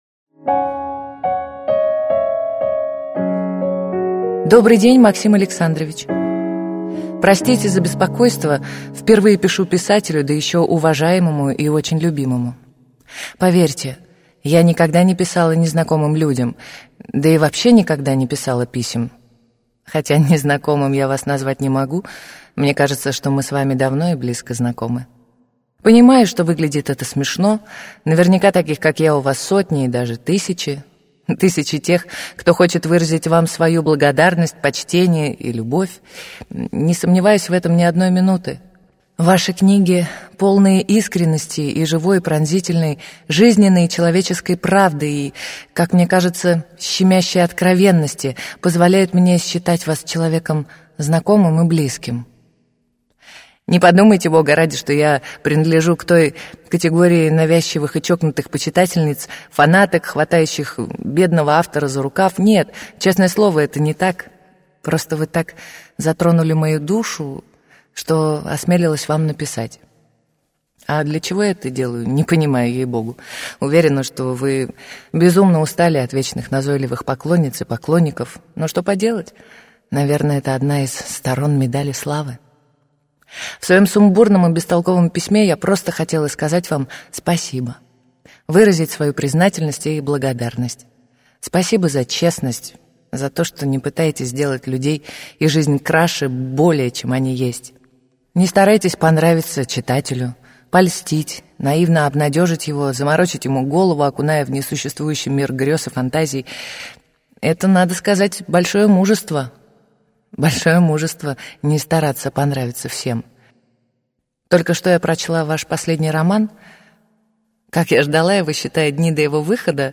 Прослушать фрагмент аудиокниги Его женщина